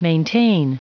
Prononciation du mot maintain en anglais (fichier audio)
Prononciation du mot : maintain